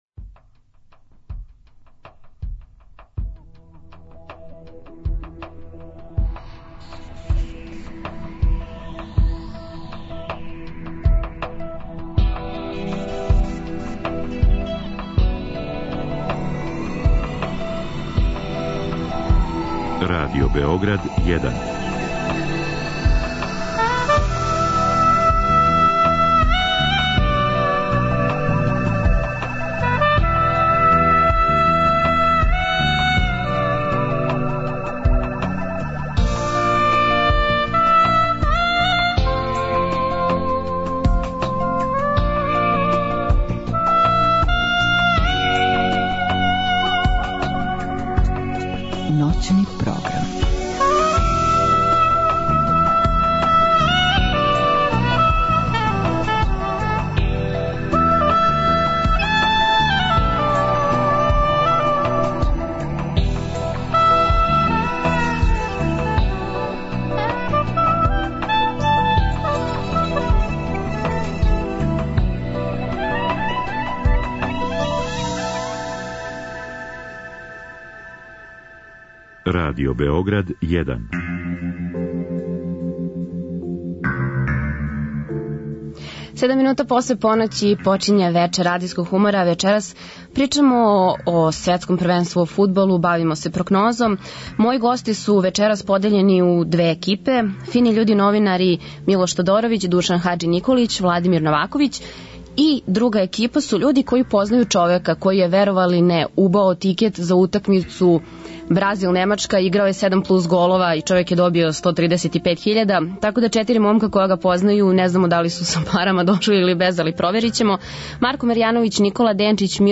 Вечерас од 00:05 на таласима Радио Београда 1 бавимо се прорицањем. О томе ко ће бити нови светски првак у фудбалу причају мушкарци, дакле селектори у најави.